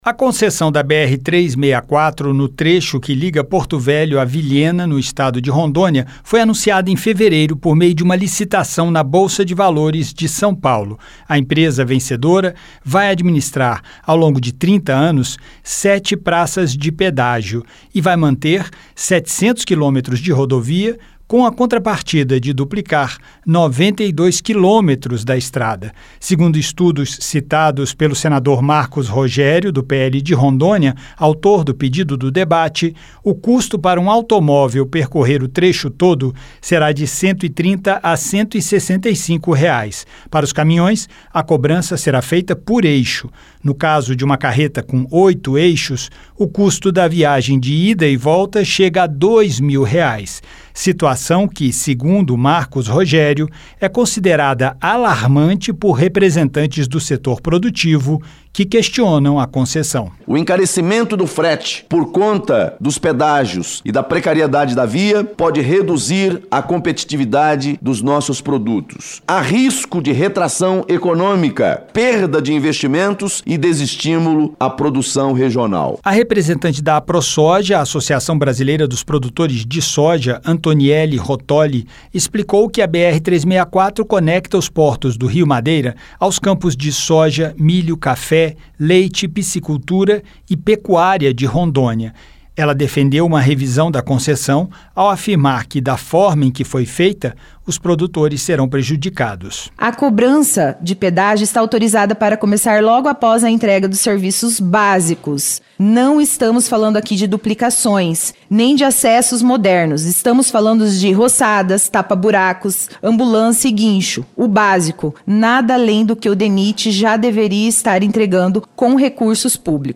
A Comissão de Infraestrutura discutiu em audiência pública a concessão da BR 364, no trecho que liga as cidades de Porto Velho e Vilhena, em Rondônia.